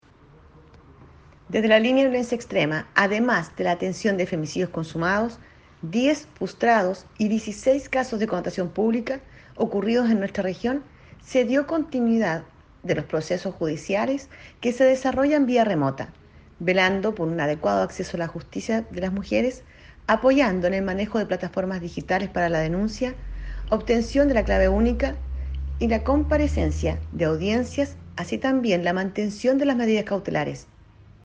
REPORTAJE-CUÑA-03-DIRECTORA-SERNAMEG-Línea-de-Violencia-Extrema.mp3